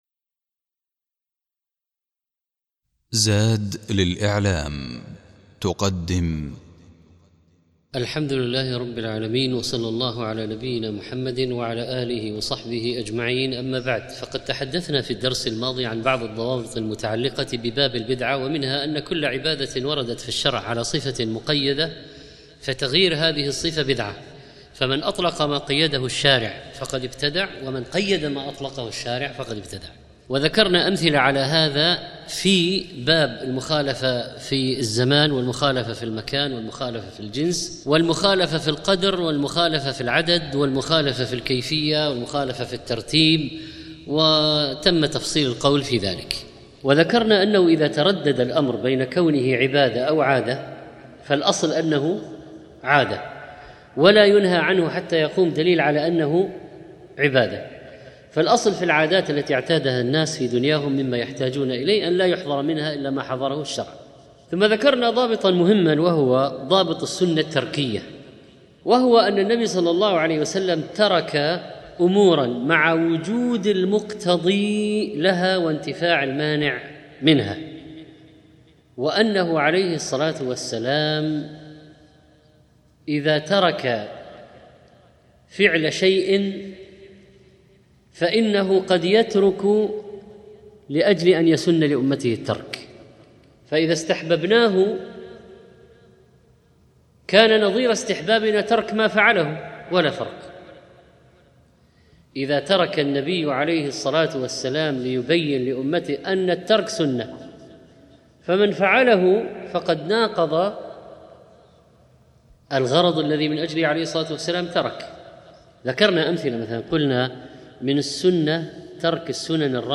17 شوّال 1435 الزيارات: 13343 تحميل تحميل ملف صوتي تحميل ملف فيديو 52- ضوابط البدعة، والانحرافات في أبواب البدعة والتبديع 4 ذكر الشيخ في هذا الدرس بعضا من الضوابط المتعلقة بباب البدعة التي منها الأمور التركية وذكر أمثلة على ذلك، كما أن الشيخ ذكر نماذج من البدع المنتشرة في الزمن المتأخر، وأن هذه البدع منها ما ينتشر عبر وسائل الاتصالات الحديثة؛ كالواتس اب، ووسائل التواصل الاجتماعي، التي تعج بهذه البدع، والتي منها يكون الخطر، وأن الناس في موضوع تلقي هذه البدع منهم من عنده علم وفقه ودين، فهو يرفضها، ويحذر من ذلك، ومنهم من يستغرب ولكنه لا يحرك ساكنا، ومنهم من يقبل ويروج لذلك، وذكر الشيخ أمثلة على ذلك.